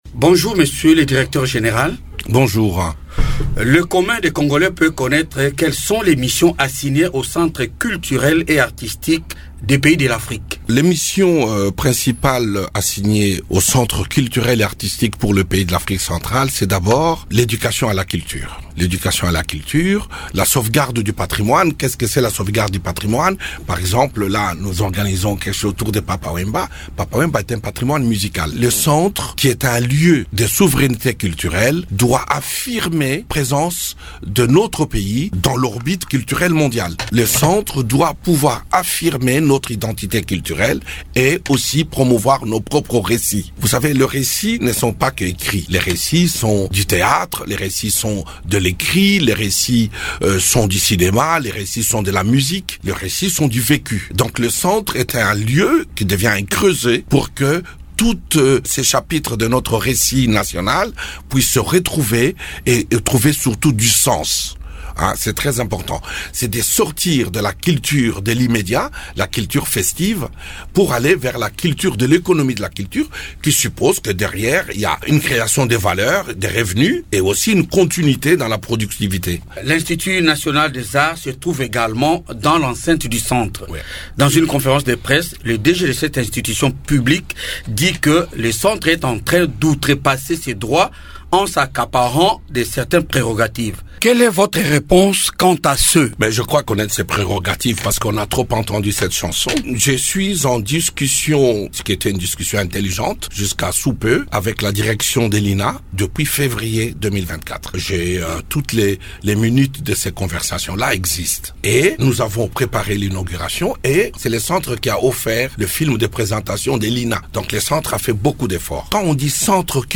Balufu Bakupa Kanyinda, directeur général du Centre culturel et artistique des pays de l’Afrique centrale, a évoqué, au cours d’un entretien avec Radio Okapi mardi 22 avril, les missions assignées à cette institution.